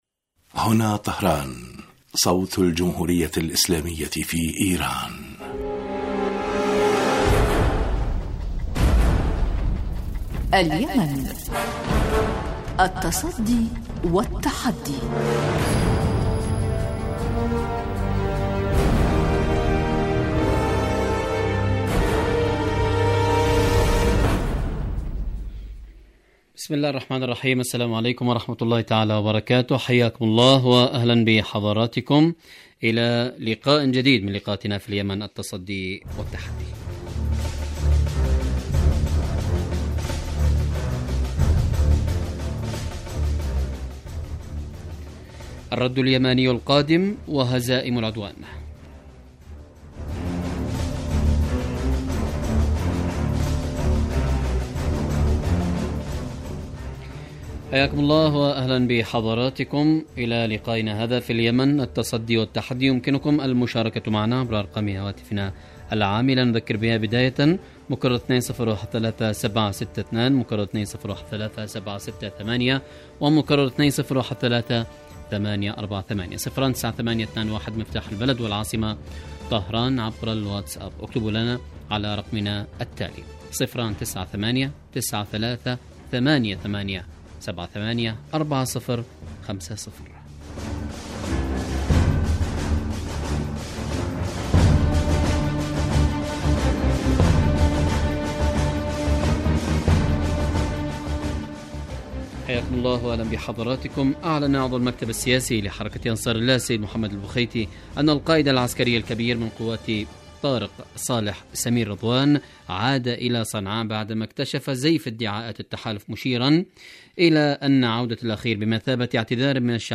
اليمن التصدي و التحدي برنامج سياسي حواري يأتيكم مساء كل يوم من إذاعة طهران صوت الجمهورية الإسلامية في ايران .
البرنامج يتناول بالدراسة و التحليل آخر مستجدات العدوان السعودي الأمريكي على الشعب اليمني بحضور محللين و باحثين في الاستوديو